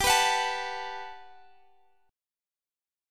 Listen to GM9 strummed